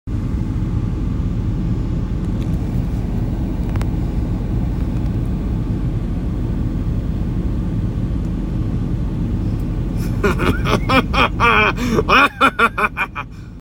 New bike sound effects free download